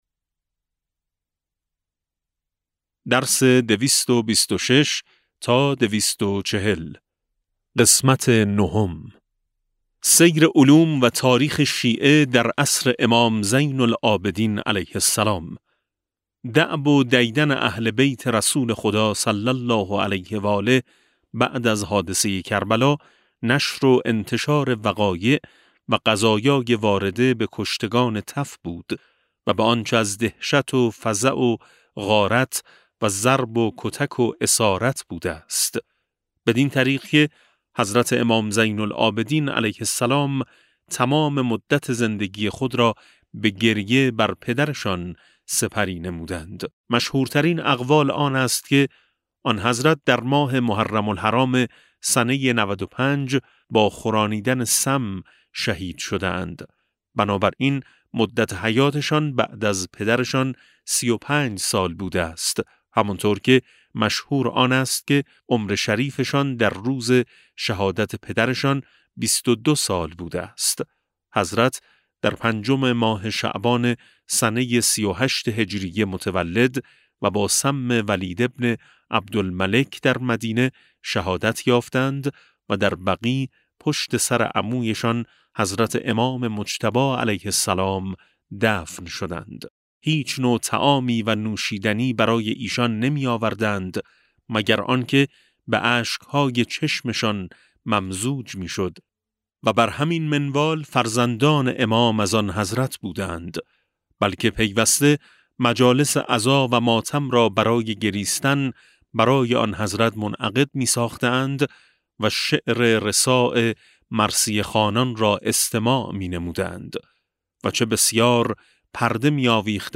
کتاب صوتی امام شناسی ج 16 و17 - جلسه9